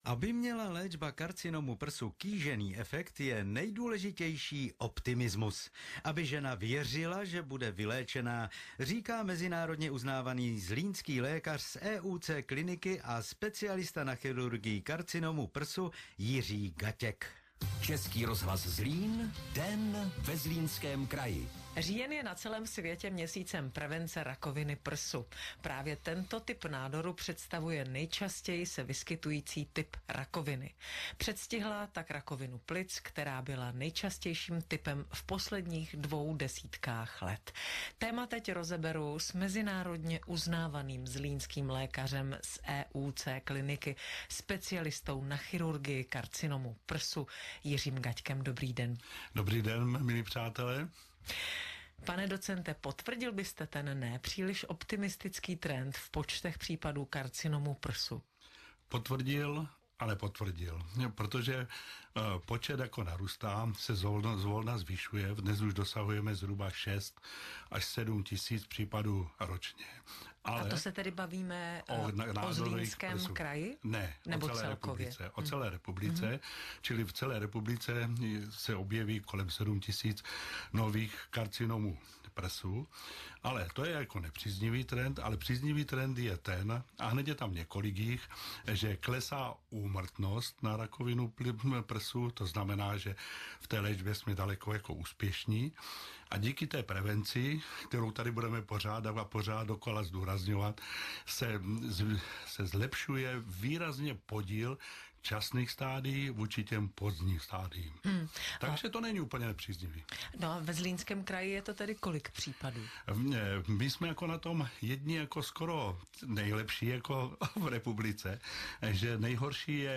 Čro Zlín Rozhovor K Tématu Rakoviny Prsu